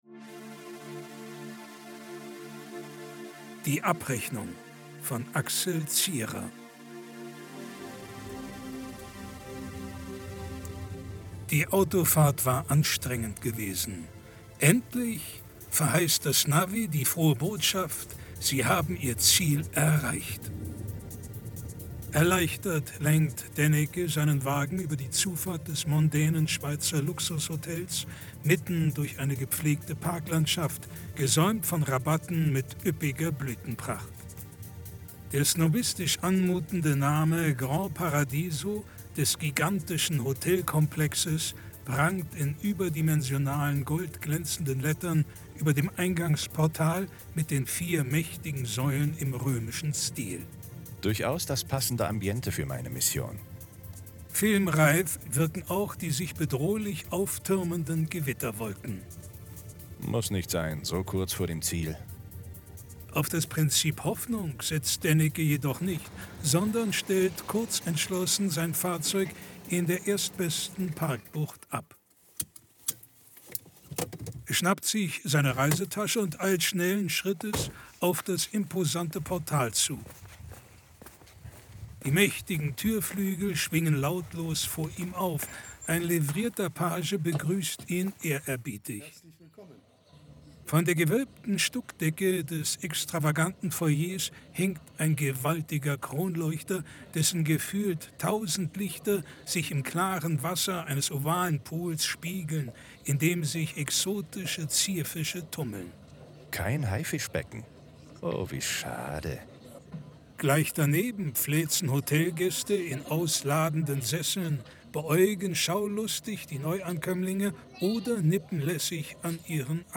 Im Gegenteil, wie mein Kurzhörspiel aufzeigt.